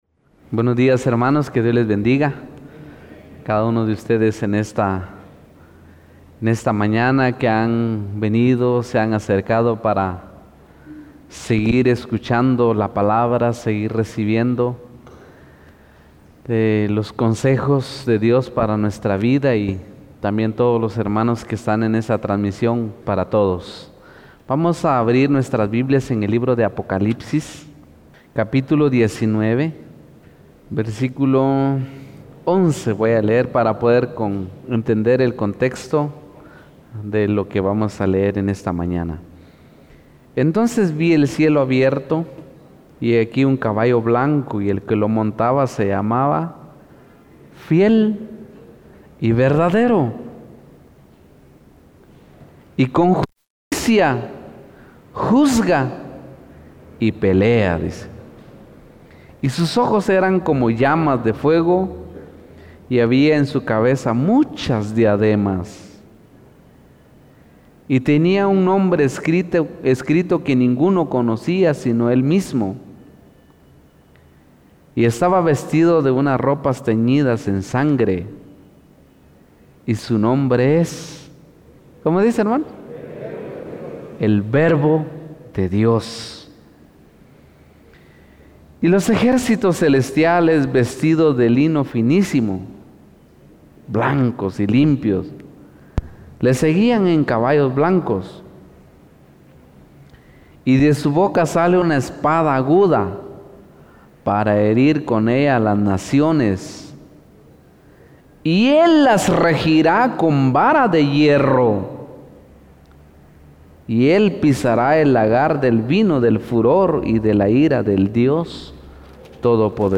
Series: Servicio General